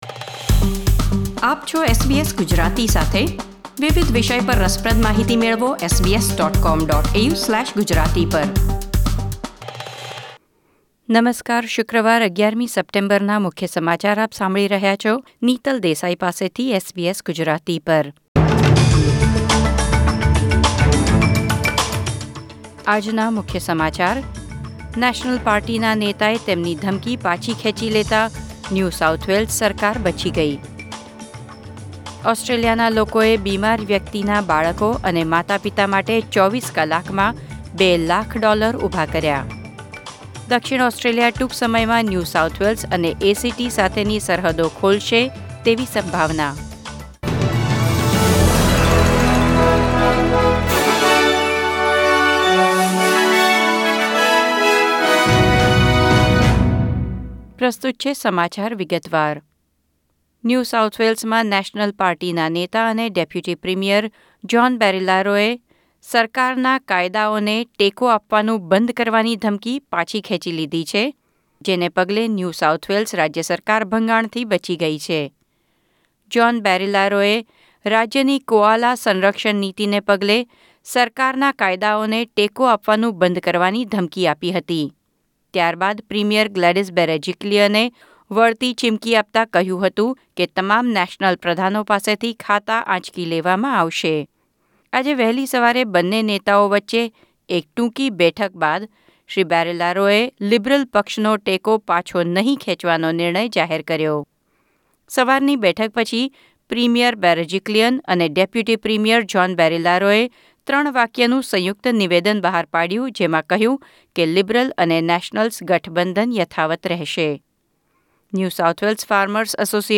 SBS Gujarati News Bulletin 11 September 2020